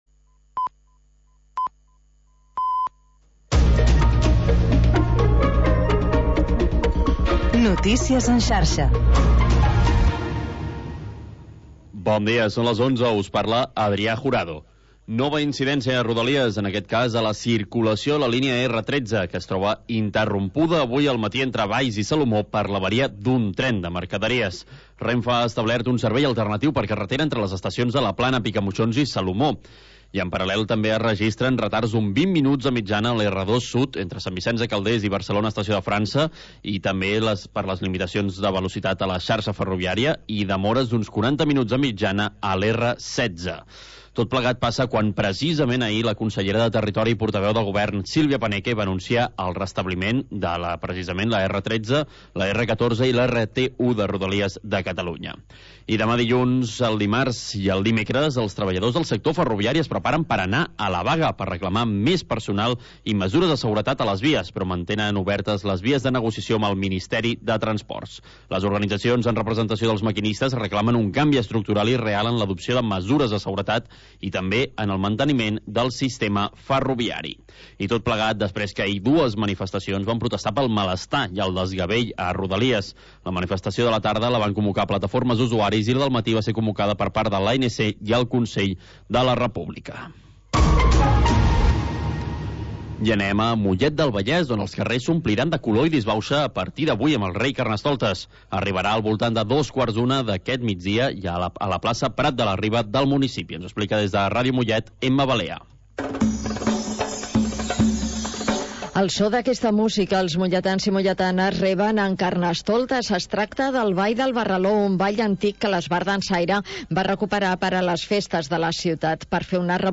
Havanera, cant de taverna i cançó marinera. obrint una finestra al mar per deixar entrar els sons més mariners